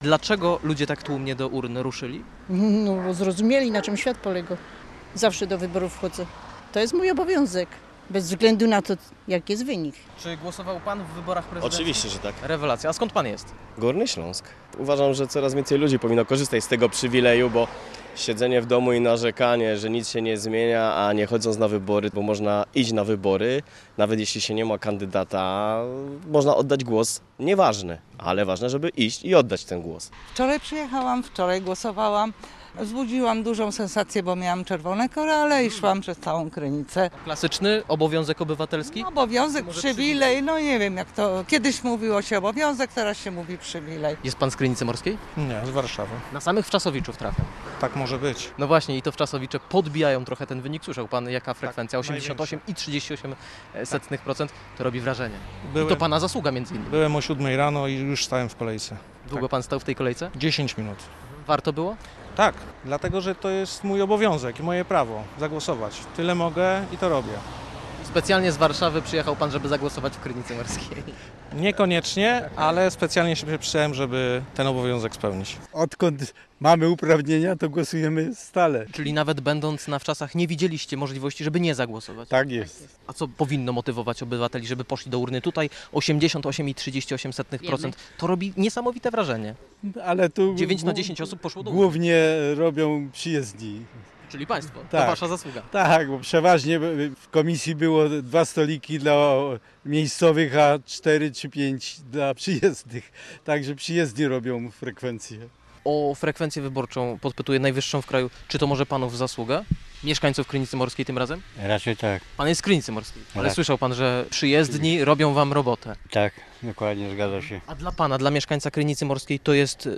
Jak wyglądało głosowanie w kurorcie? O to nasz reporter pytał mieszkańców i turystów.